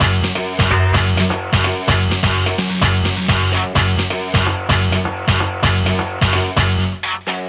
Nines_128_F#_Dry.wav